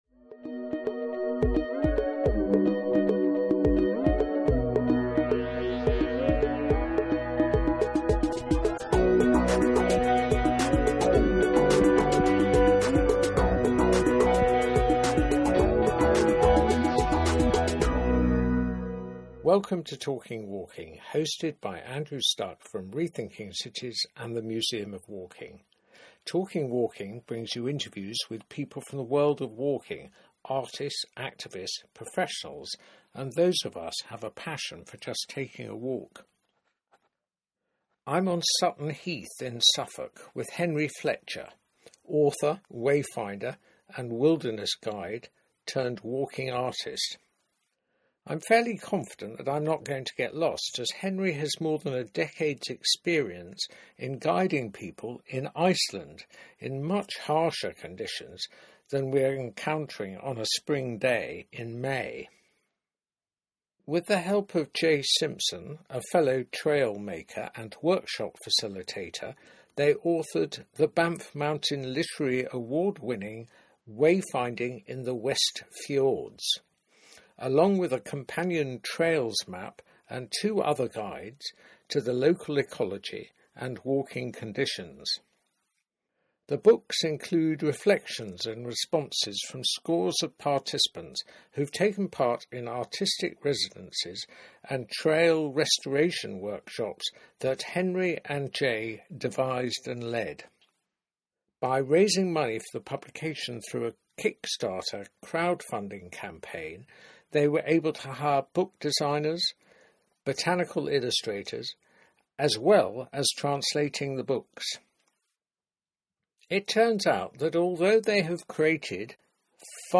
on Sutton Heath in Suffolk